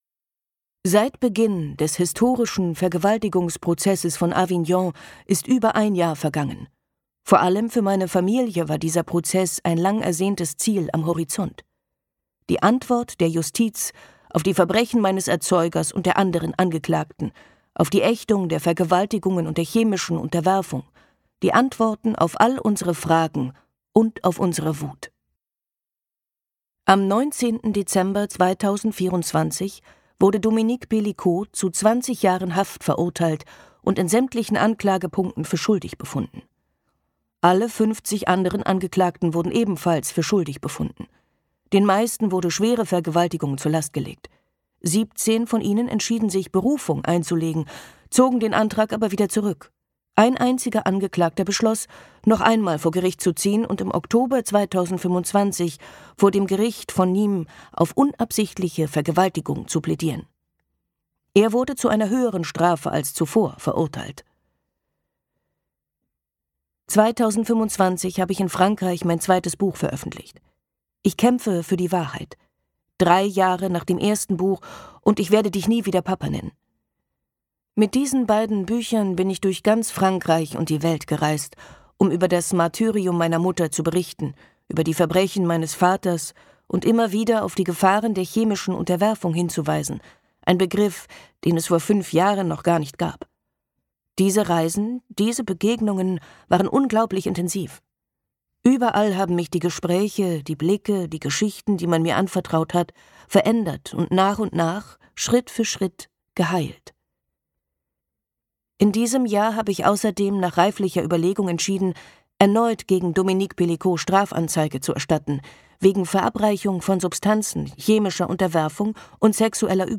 Ich kämpfe für die Wahrheit - Caroline Darian | argon hörbuch
Gekürzt Autorisierte, d.h. von Autor:innen und / oder Verlagen freigegebene, bearbeitete Fassung.